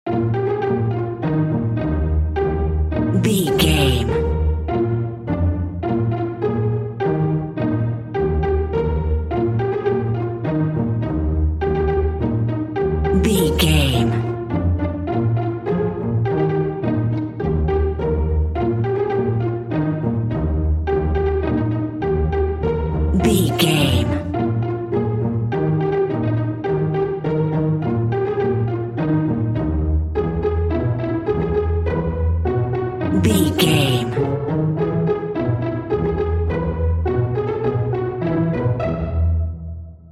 Ionian/Major
E♭
nursery rhymes
kids music